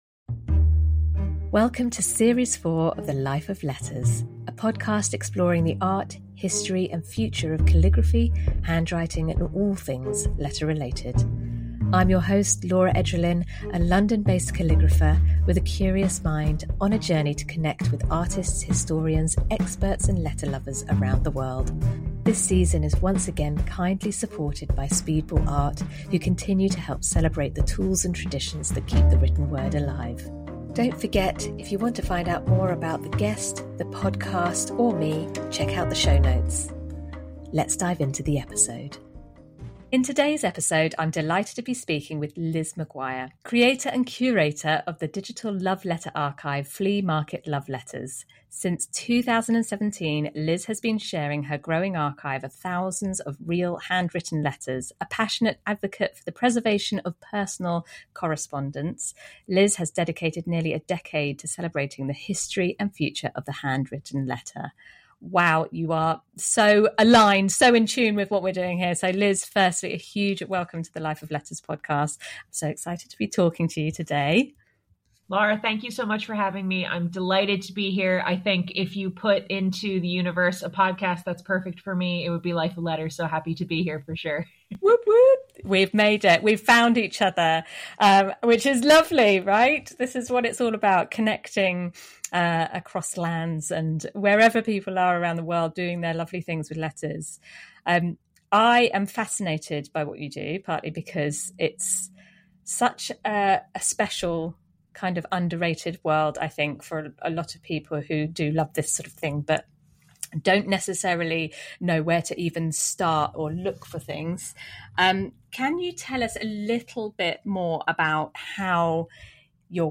The conversation delves into the emotional and historical context of letters, the challenges of preserving personal correspondence, and the importance of storytelling in connecting past and present.